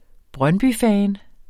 Udtale [ ˈbʁɶnbyˌfæːn ]